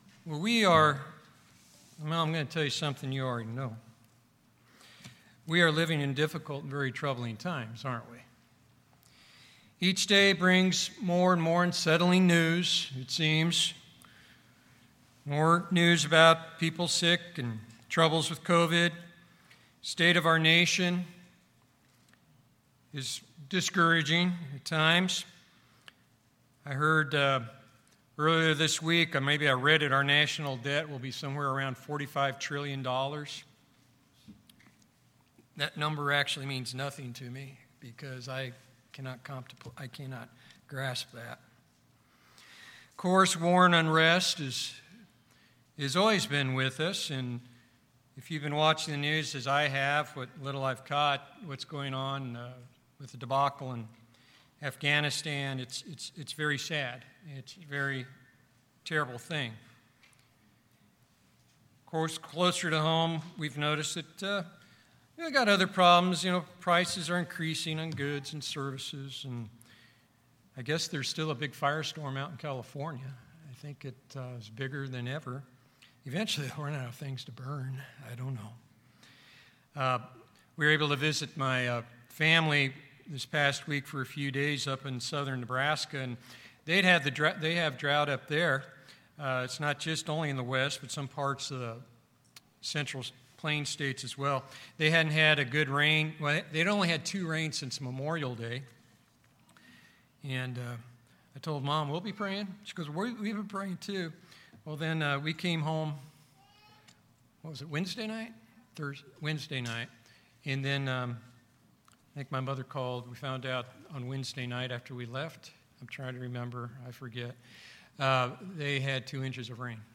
This sermon addresses those questions and presents the assurance from Scripture that God does hear and answer when we call upon Him in faith and truth.